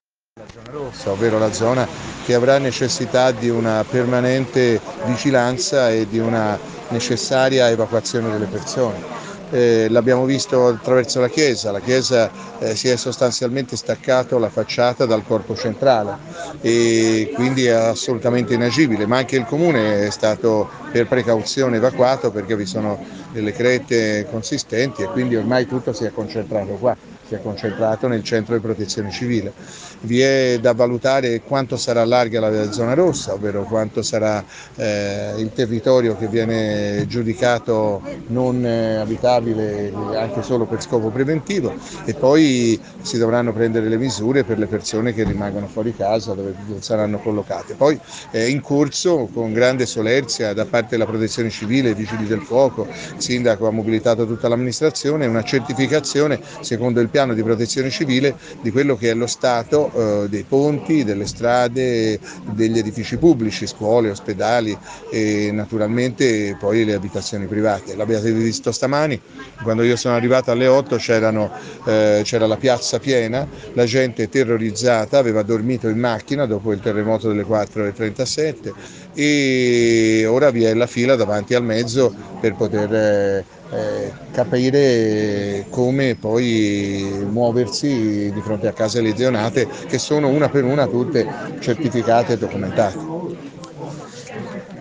Barberino infatti risulta il comune più colpito dal sisma, come ha sottolineato anche, al termine dell’incontro, il presidente del Consiglio regionale Eugenio Giani.